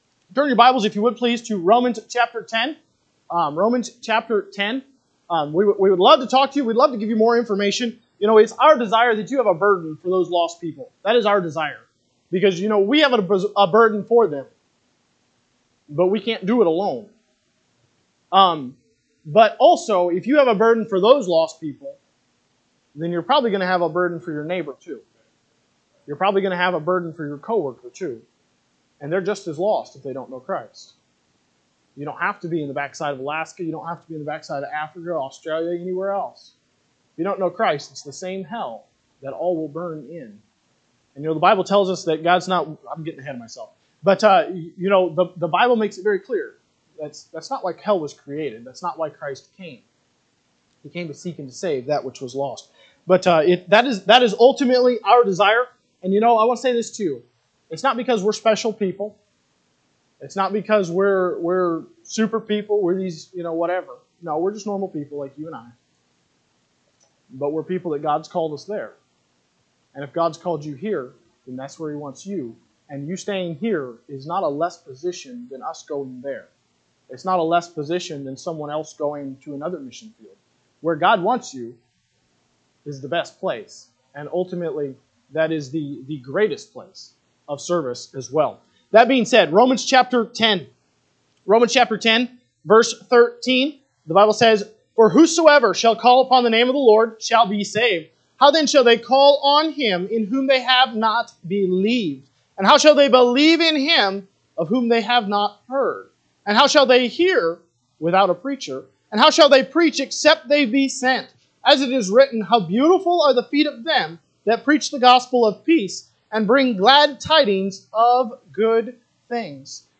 February 23, 2025 am Service Romans 10:13-17 (KJB) 13 For whosoever shall call upon the name of the Lord shall be saved. 14 How then shall they call on him in whom they have not believed?…
Sunday AM Message